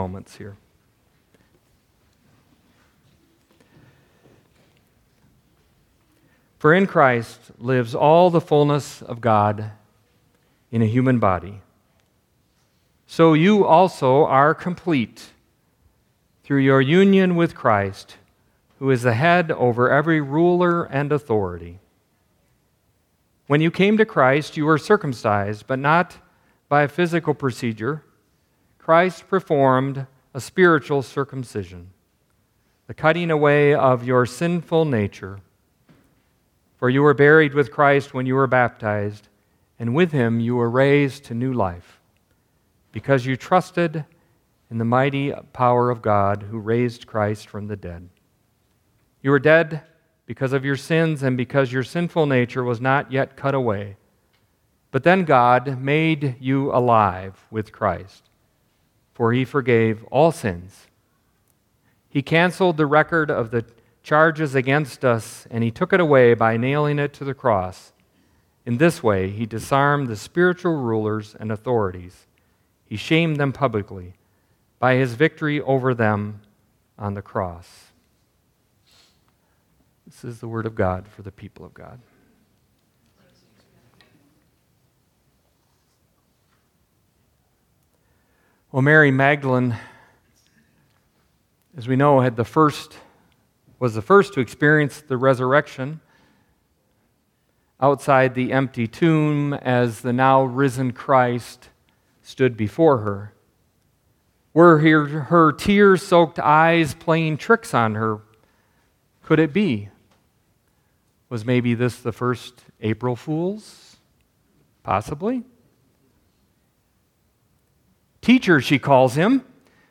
Ortonville United Methodist Church Weekly Messages